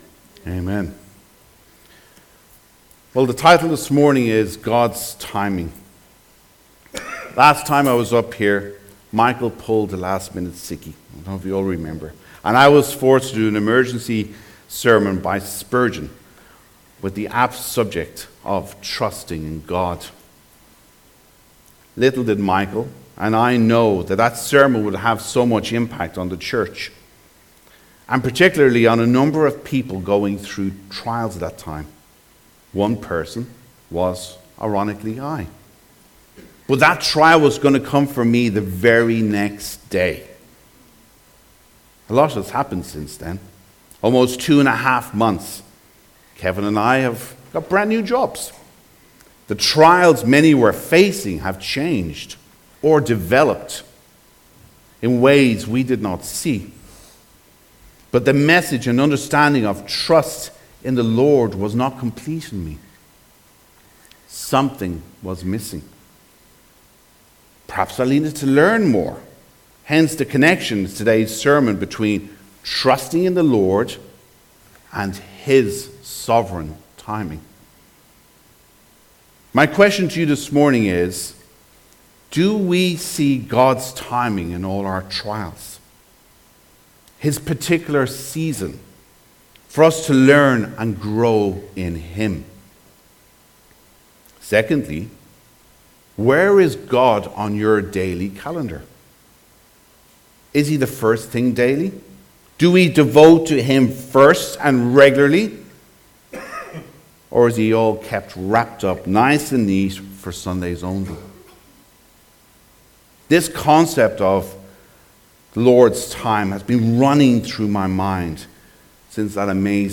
Carol Service 2024.